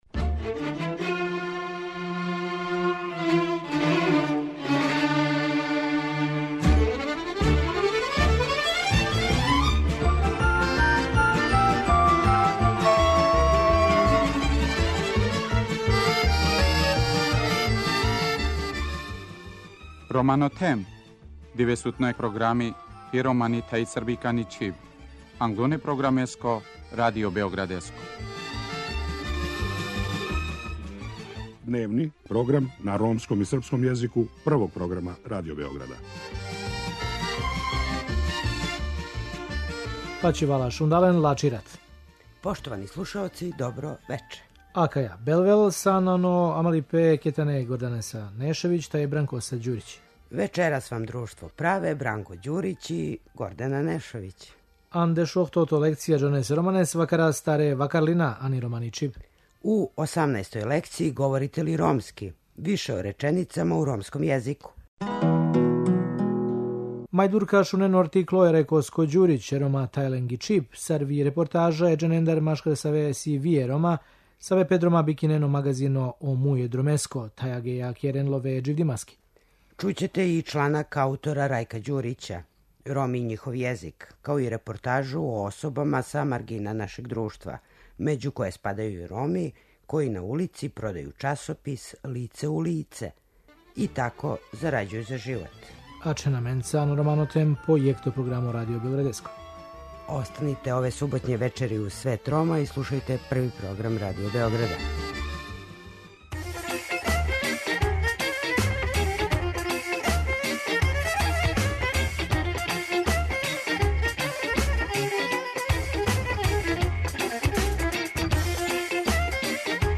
У 18. лекцији 'Говорите ли ромски?', више о реченицама у ромском језику. Чућете и чланак аутора Рајка Ђурића 'Роми и њихов језик', као и репортажу о особама са маргина нашег друштва, међу које спадају и Роми, који на улици продају часопис 'Лице улице' и тако зарађују за живот.